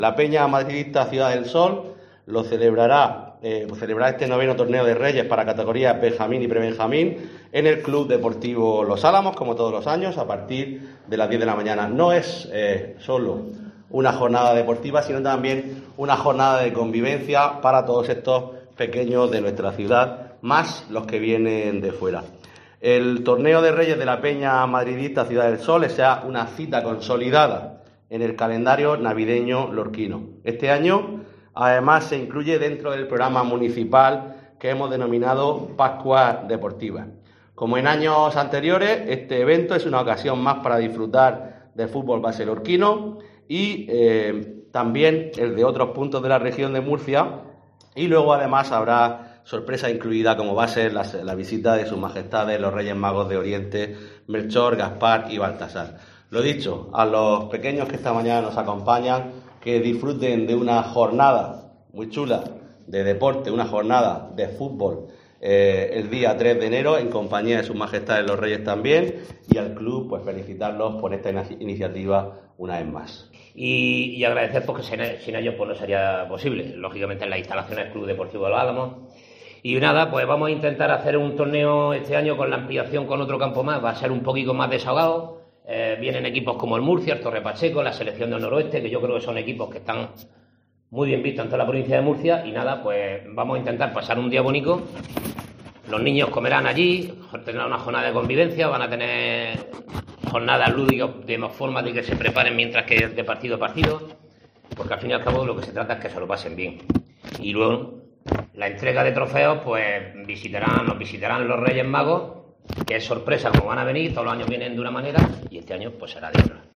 Juan Miguel Bayonas, concejal deportes Ayto Lorca